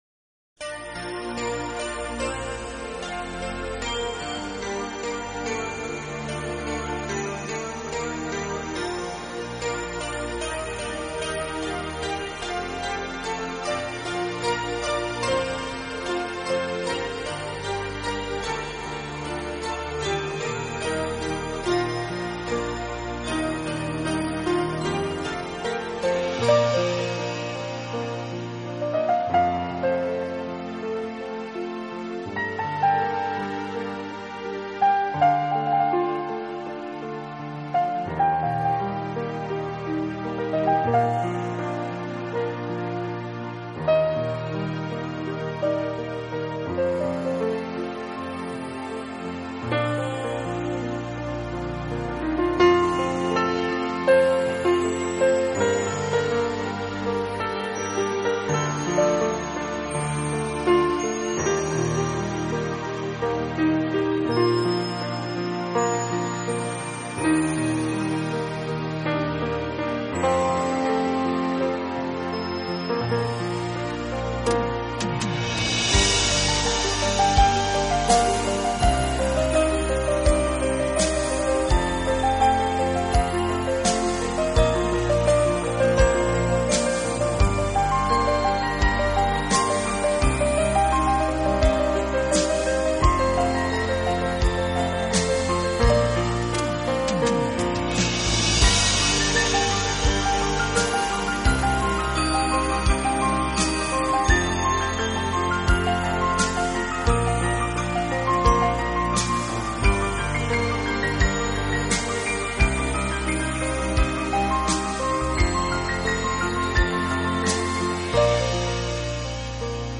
【新世纪钢琴】
音乐风格：钢琴